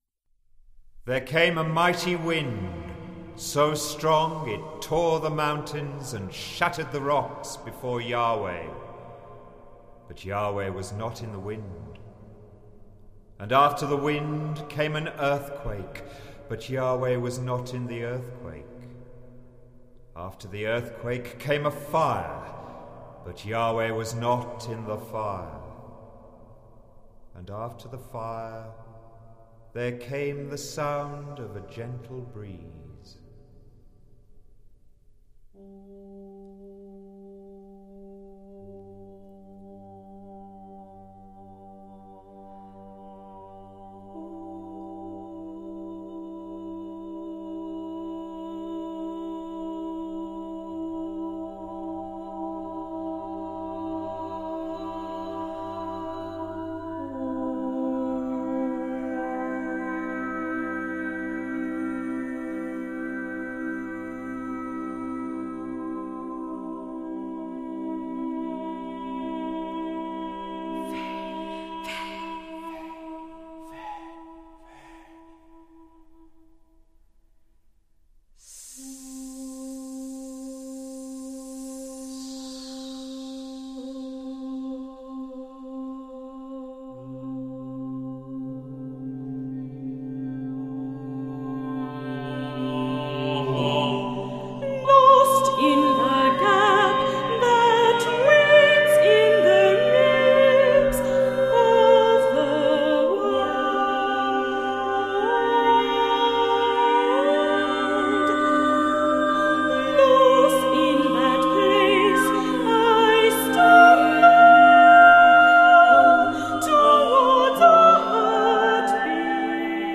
for mixed voices
live recording